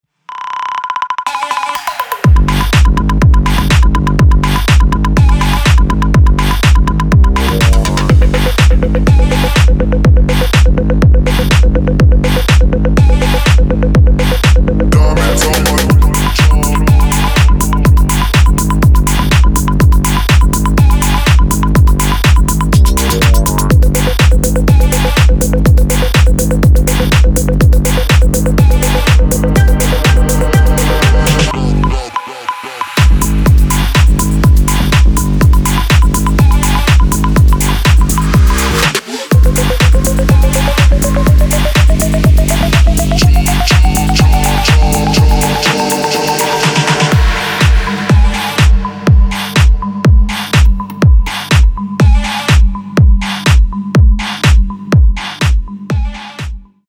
мужской голос
громкие
электронная музыка
мощные басы
Bass House
качающие
garage